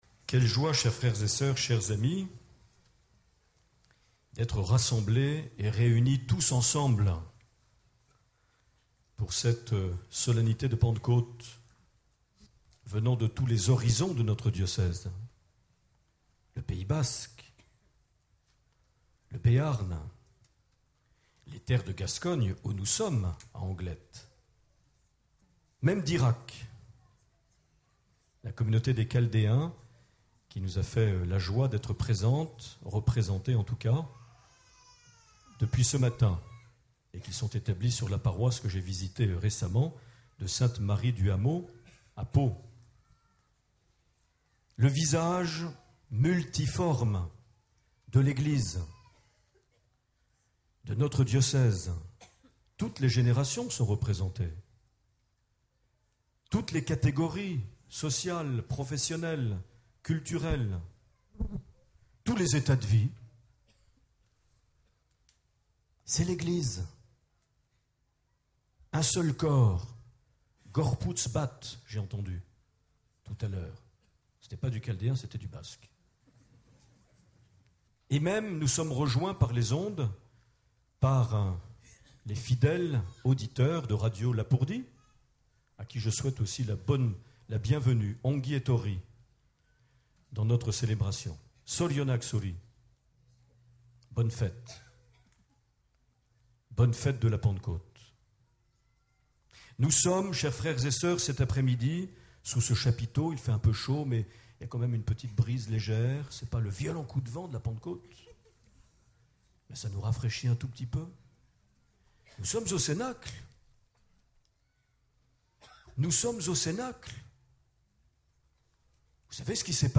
12 juin 2011 - Notre Dame du Refuge - Anglet - Messe de Pentecôte - Rassemblement diocésain "Famille en fête"
Accueil \ Emissions \ Vie de l’Eglise \ Evêque \ Les Homélies \ 12 juin 2011 - Notre Dame du Refuge - Anglet - Messe de Pentecôte - (...)